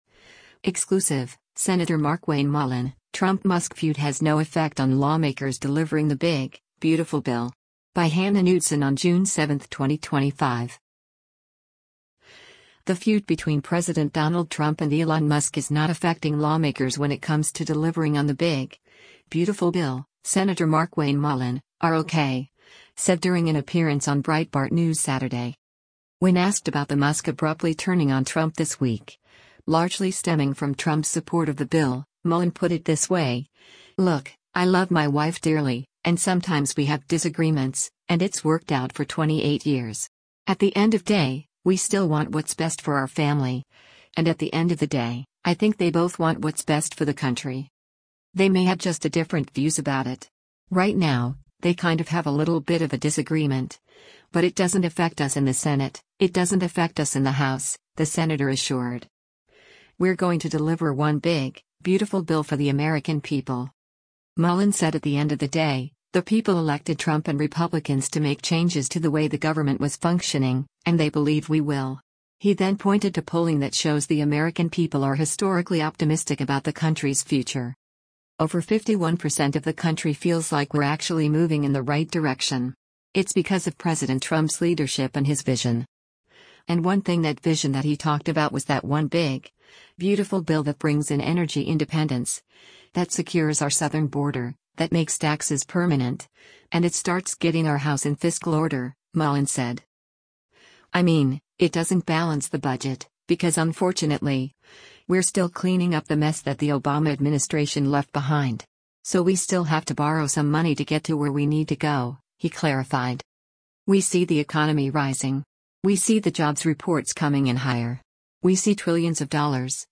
The feud between President Donald Trump and Elon Musk is not affecting lawmakers when it comes to delivering on the “big, beautiful bill,” Sen. Markwayne Mullin (R-OK) said during an appearance on Breitbart News Saturday.
Breitbart News Saturday airs on SiriusXM Patriot 125 from 10:00 a.m. to 1:00 p.m. Eastern.